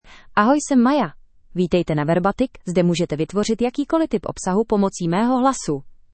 FemaleCzech (Czech Republic)
MayaFemale Czech AI voice
Maya is a female AI voice for Czech (Czech Republic).
Voice sample
Listen to Maya's female Czech voice.
Female